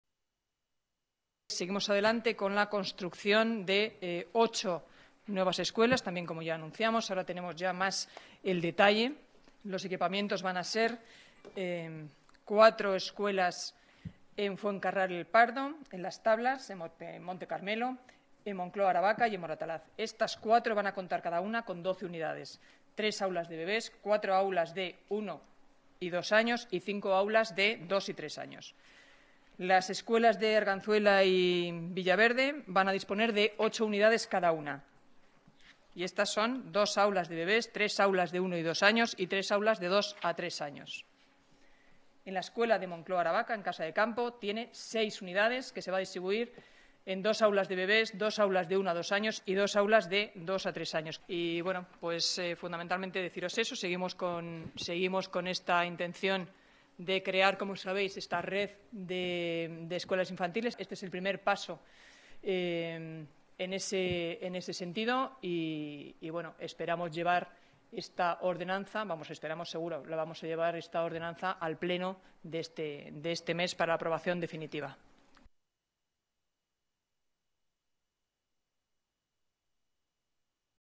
Nueva ventana:Higueras habla de las nuevas escuelas infantiles